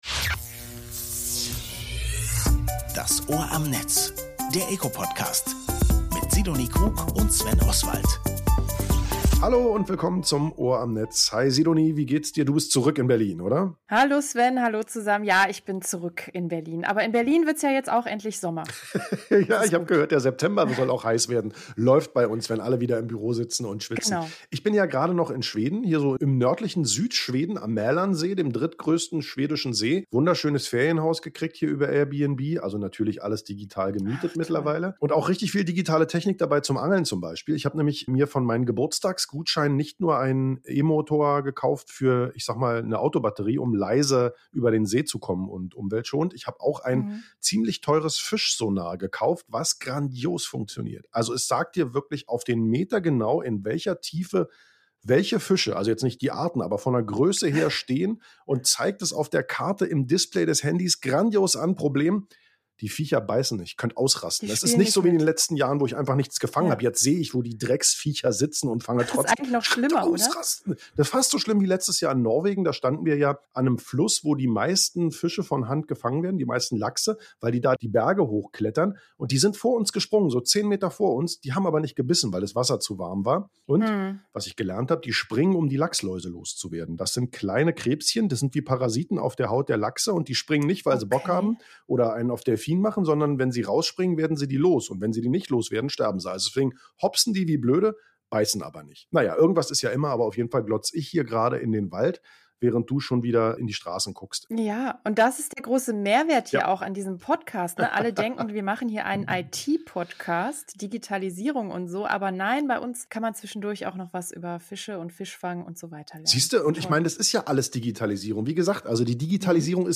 Anlässlich der ISD 2023 am 21. & 22. September sprechen drei Speaker:innen in der neuen Folge von “Das Ohr am Netz” über Cybersicherheit.